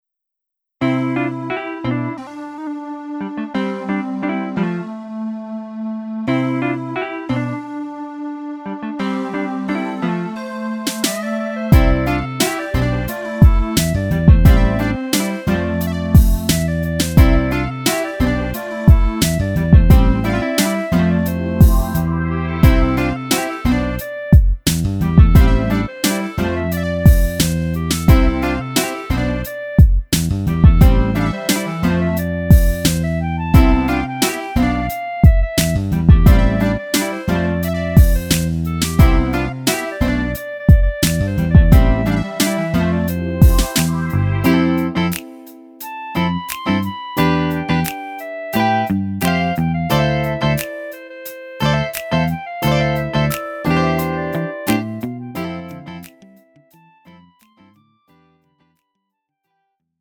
음정 원키 3:43
장르 가요 구분 Lite MR